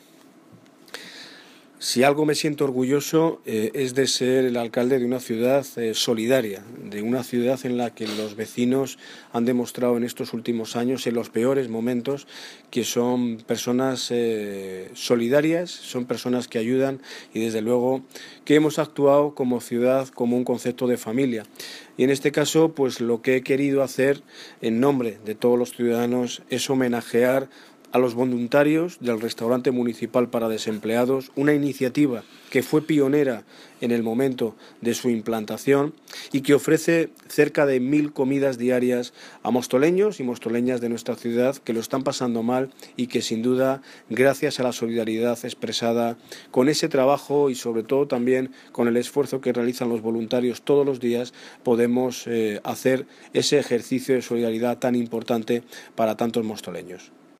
Audio de Daniel Ortiz, Alcalde de Móstoles